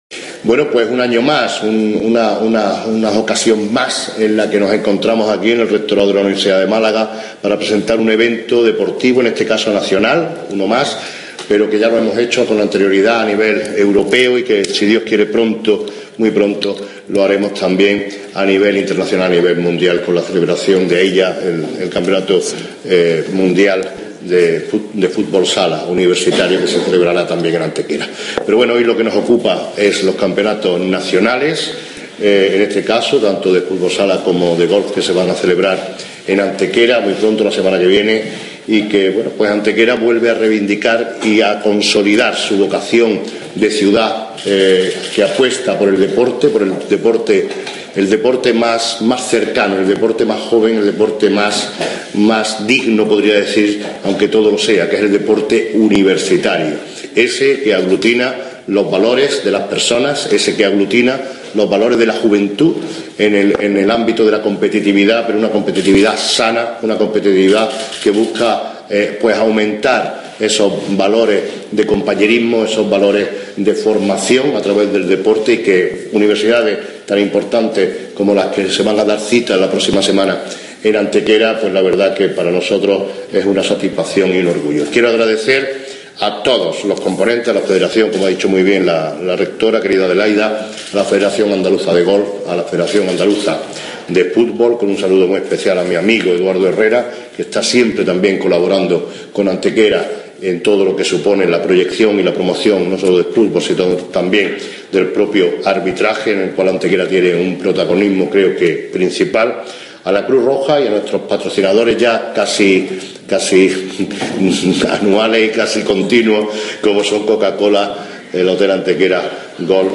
También se incluye corte de audio con la intervención del alcalde Manolo Barón.